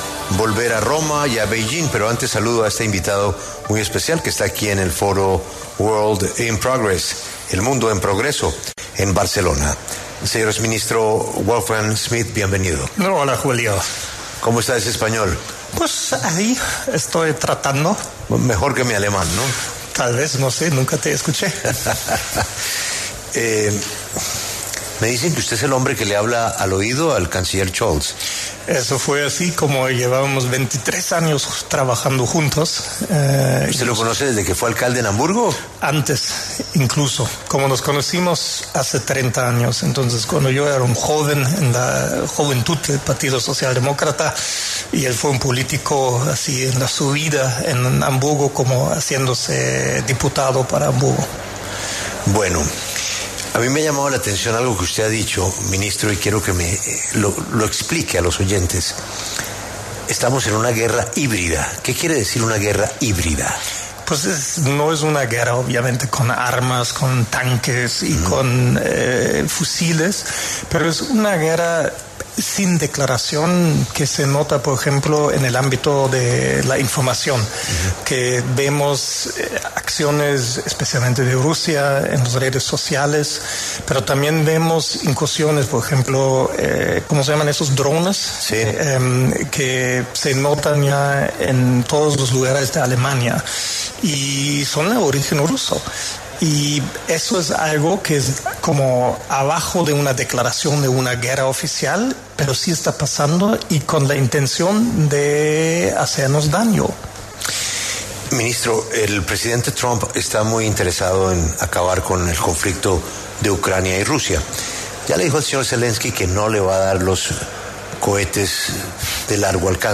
En el marco del Foro World in Progress en la ciudad de Barcelona, Wolfgang Schmidt, exministro alemán de Asuntos Especiales, habló en La W sobre la “guerra híbrida”.